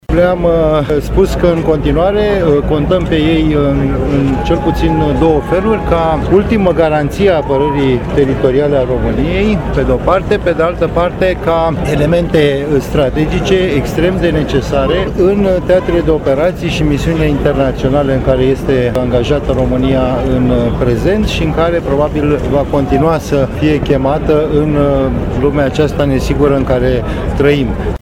Momentele festive s-au desfășurat în Piața Sfatului, în fața tribunei oficiale și a numeroșilor privitori.